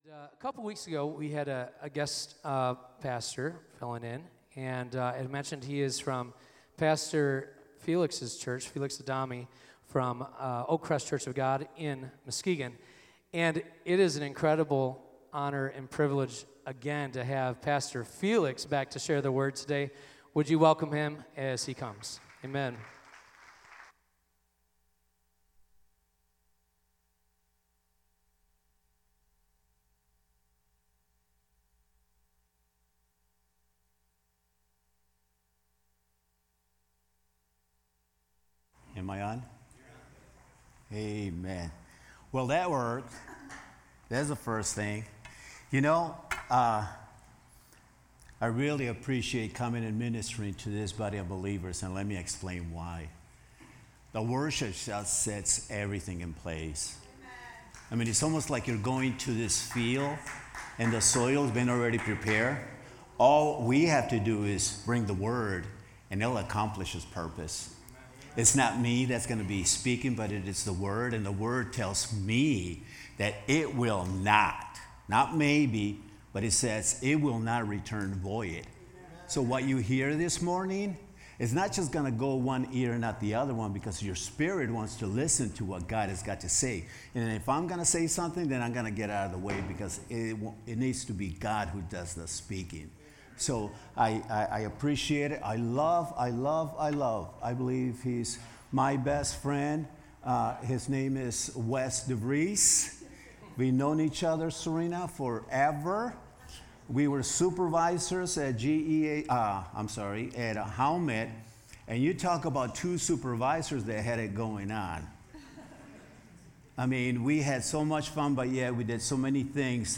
Sermon-8-18-24.mp3